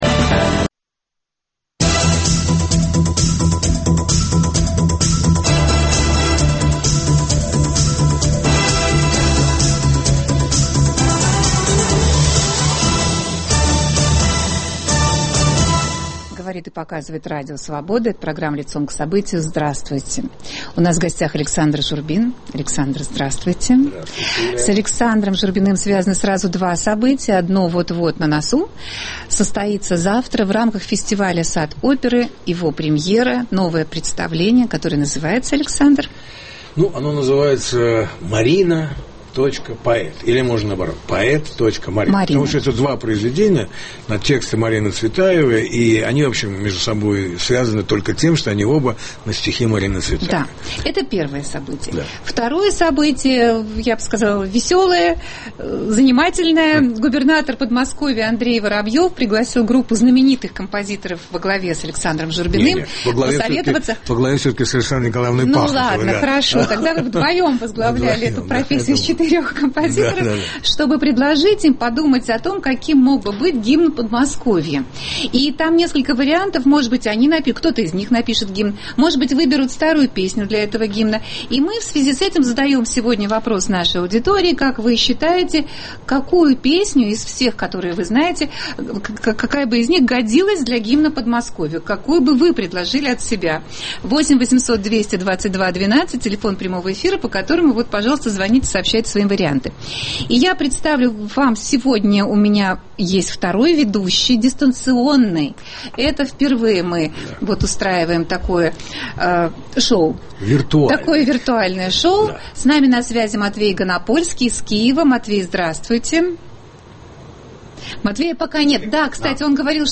Гость студии - композитор Александр Журбин. В передаче также участвует журналист радиостанции "Эхо Москвы" Матвей Ганапольский.